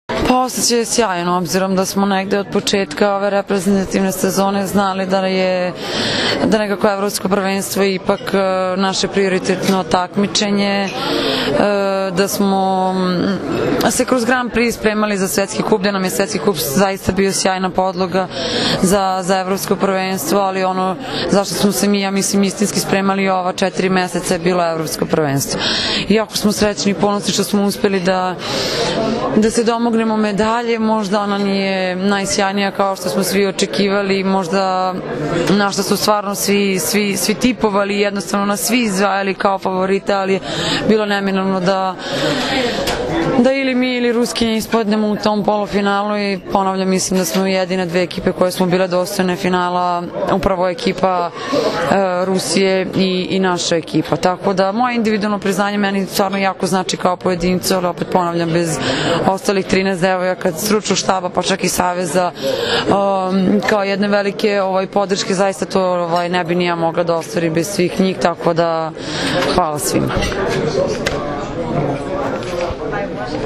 Odbojkašice su zatim produžile u beogradski hotel „M“, gde im je priređen svečani doček.
IZJAVA MAJE OGNJENOVIĆ